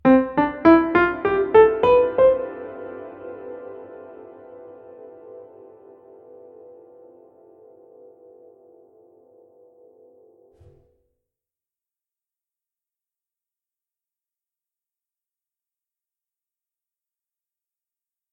Par comparaison, une gamme sur Pianoteq qui a aussi une pédale harmonique :
harmonic.mp3